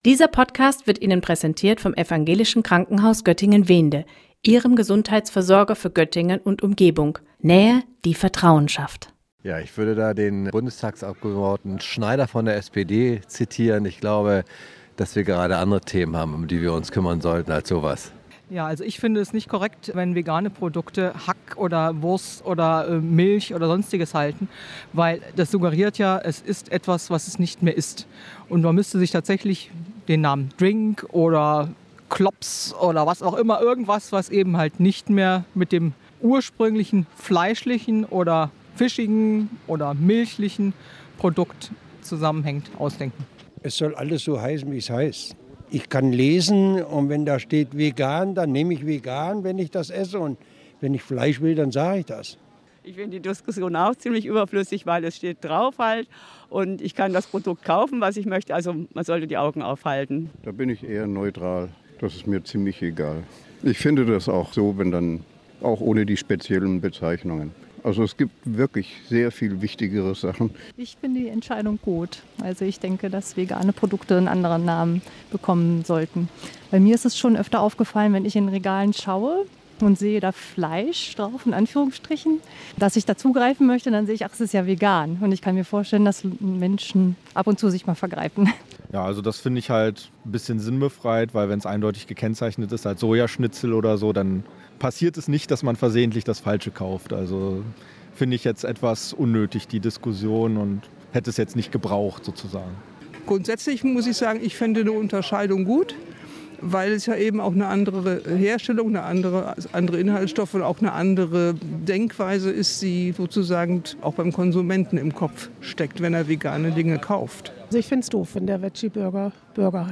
Unsere Reporter haben sich in der Göttinger Innenstadt umgehört.